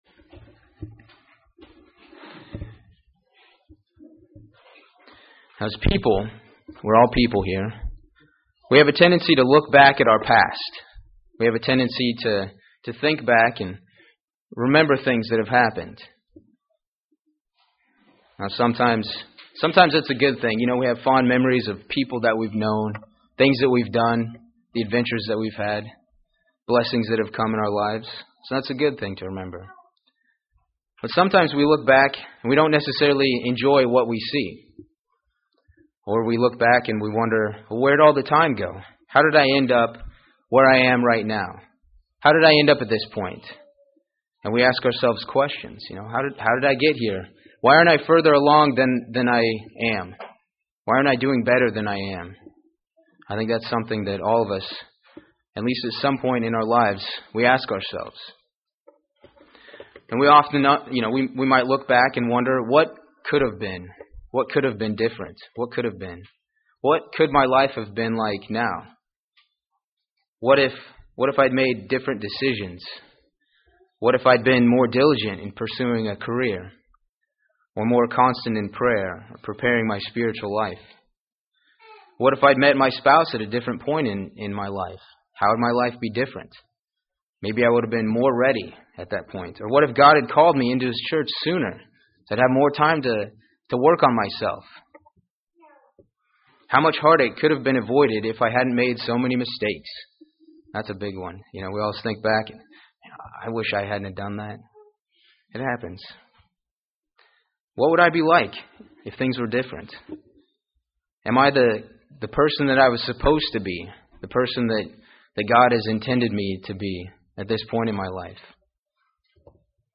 Sermons
Given in Huntsville, AL